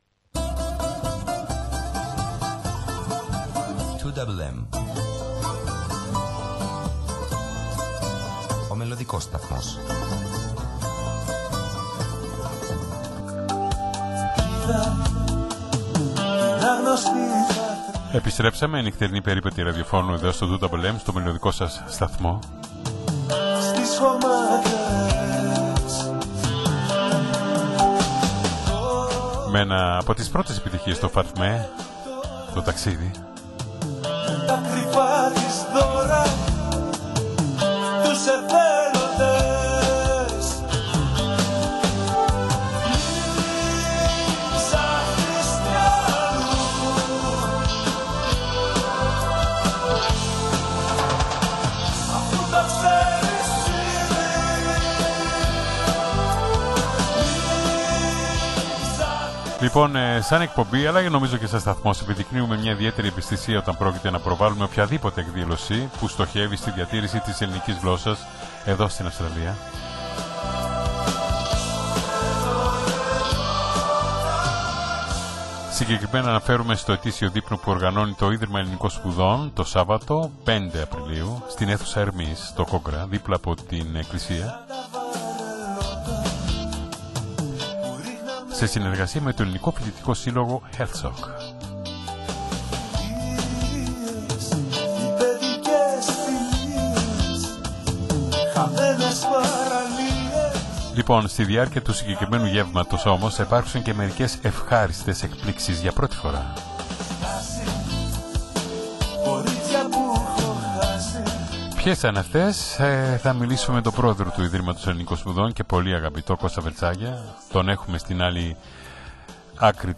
Με τον άμεσο, πληθωρικό και ειλικρινή του λόγο